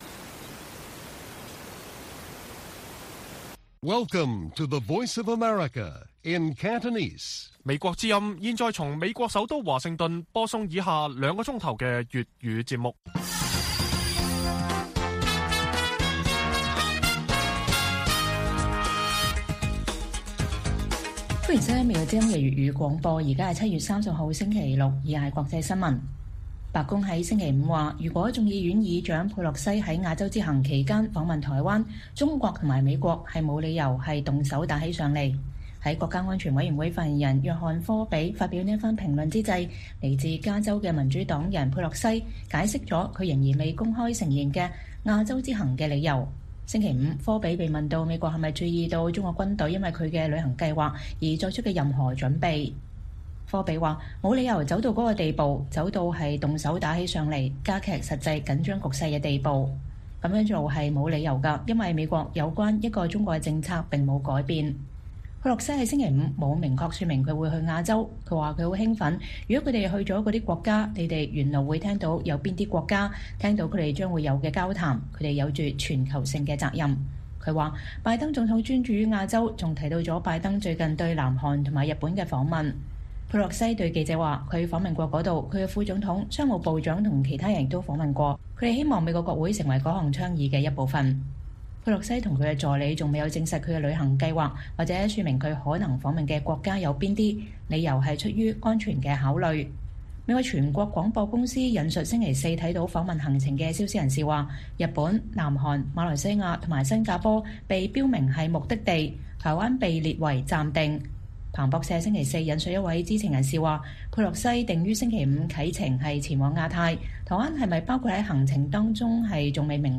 粵語新聞 晚上9-10點: 白宮：佩洛西議長若訪台灣，沒理由“動手打起來”